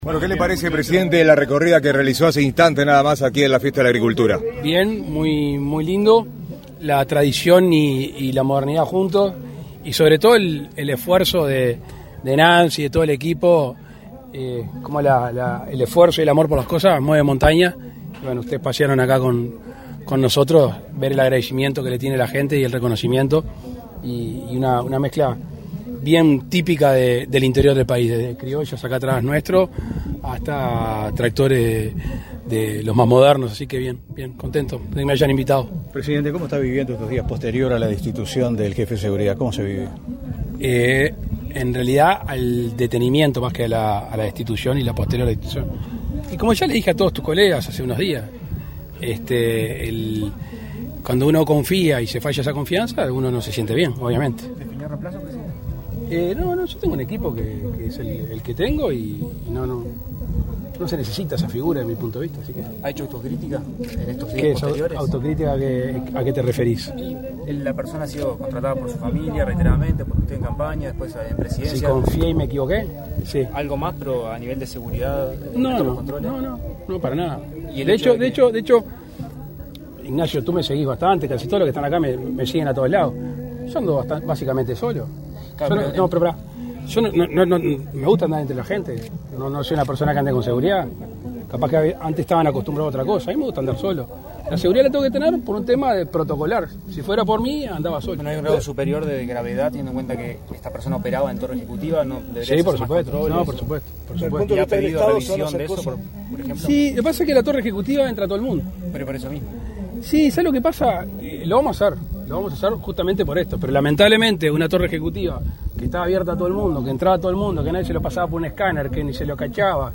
Declaraciones a la prensa del presidente de la República, Luis Lacalle Pou
Declaraciones a la prensa del presidente de la República, Luis Lacalle Pou 02/10/2022 Compartir Facebook X Copiar enlace WhatsApp LinkedIn El presidente de la República, Luis Lacalle Pou, participó, este 2 de octubre, en la 5.ª Fiesta de la Agricultura, en Carmelo. Tras el evento, realizó declaraciones a la prensa.